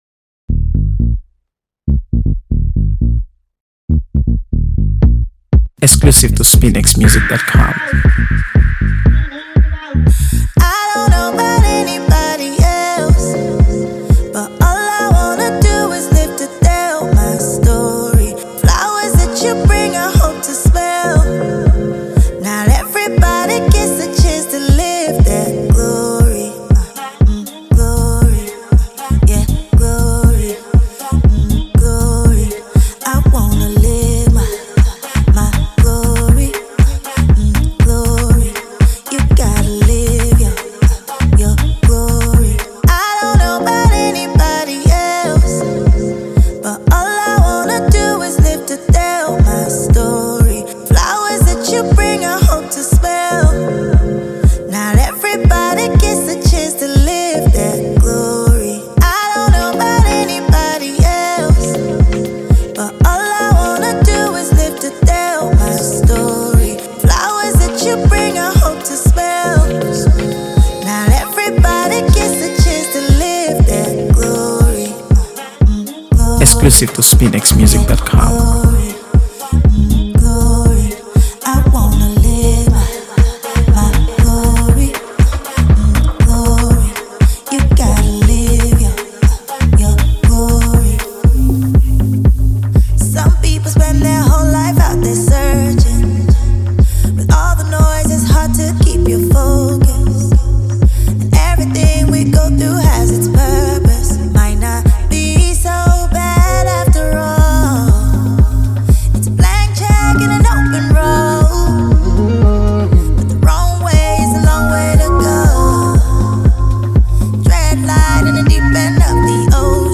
AfroBeats | AfroBeats songs
delivers an empowering and emotive performance